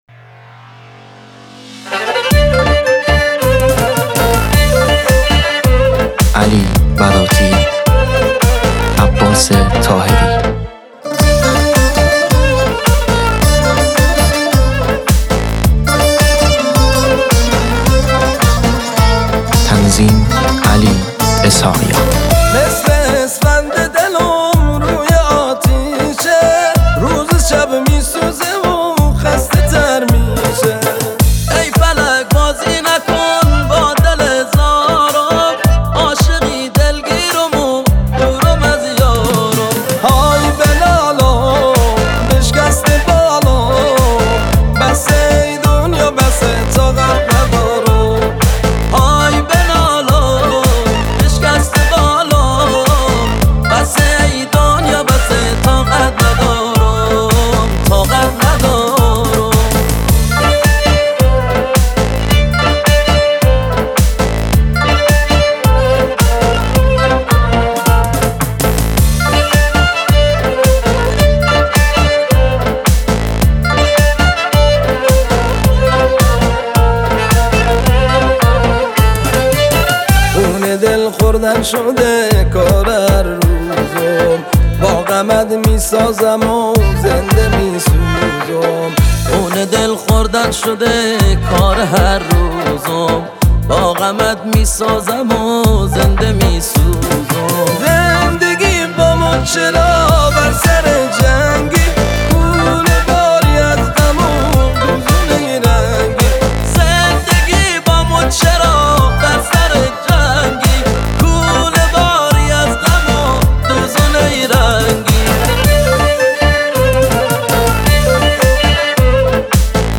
یکی از خواننده های پرشور و پرانرژی کرمانجی